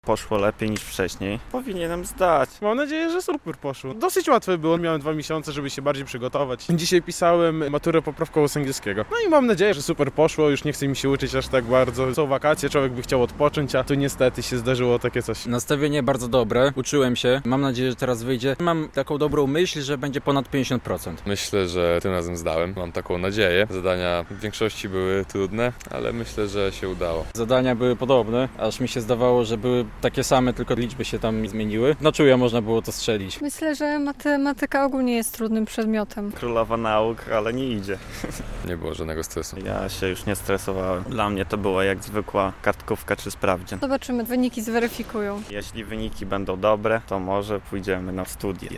Wrażenia maturzystów po egzaminie poprawkowym - relacja